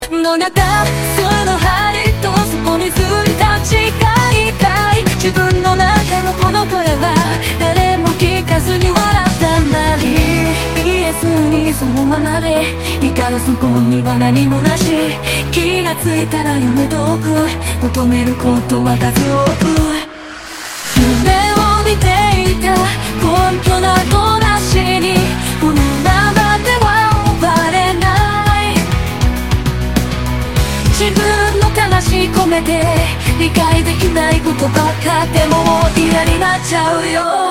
なんかキンハー感が凄い、、、